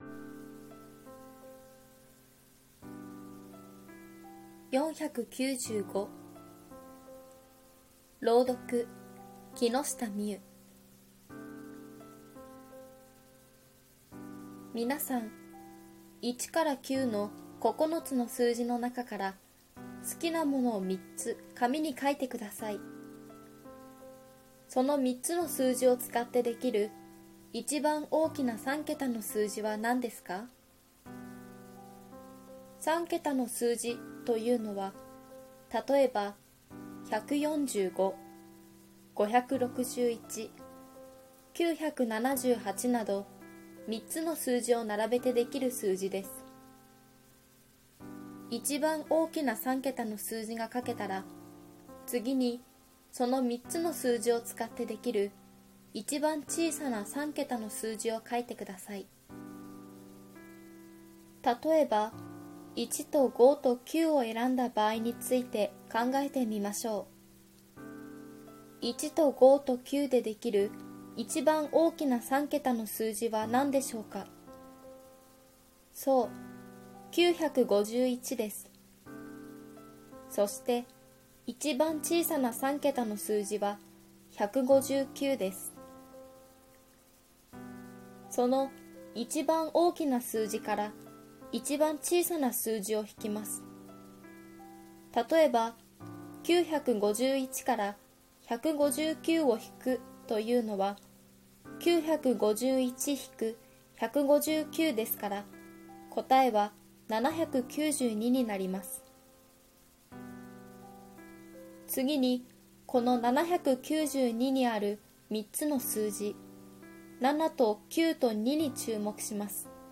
ろうどく